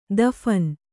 ♪ daphan